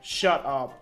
shutup.mp3